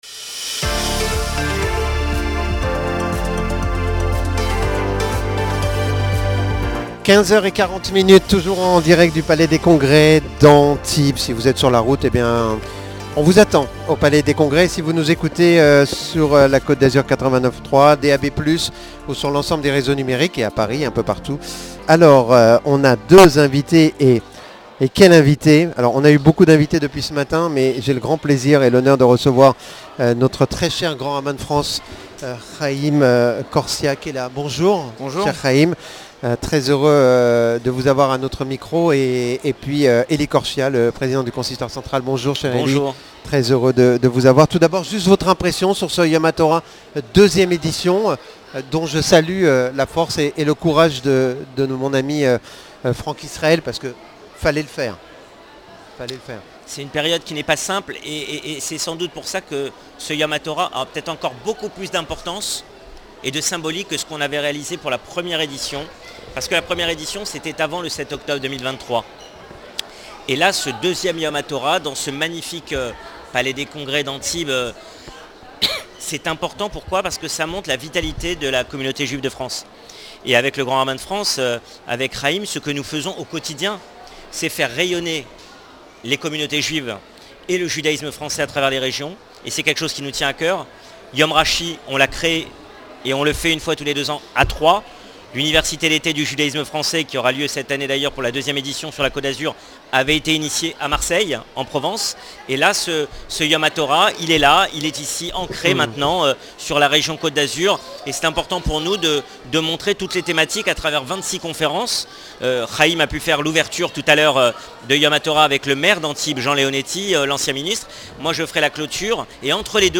Haim KORSIA, Grand Rabbin de France et Maître Elie KORCHIA, Président du Consistoire Central de France en direct depuis Yom Hatorah au Palais des Congrès d'Antibes - RCN - Ecoutez le monde qui bouge Interviews
Haim KORSIA, Grand Rabbin de France et Maître Elie KORCHIA, Président du Consistoire Central de France en direct depuis Yom Hatorah au Palais des Congrès d'Antibes